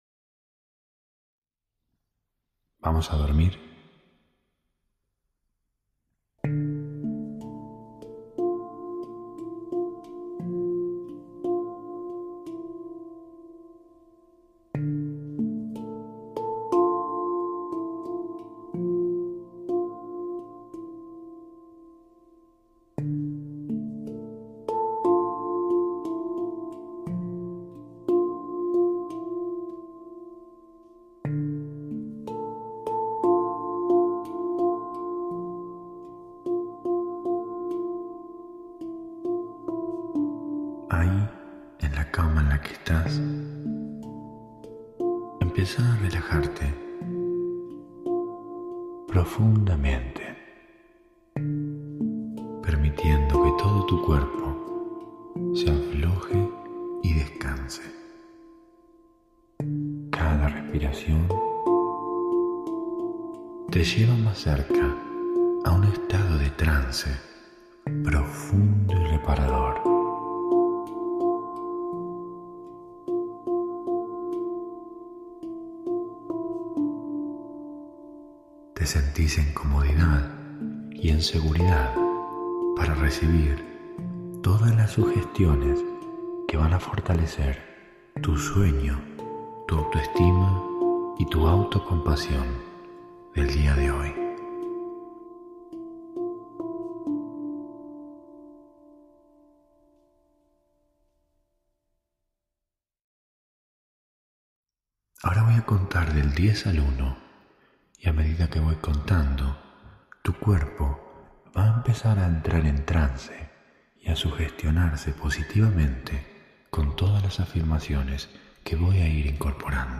Hipnosis guiada para dormir. [Altamente recomendable escucharlo con auriculares ] Hosted on Acast.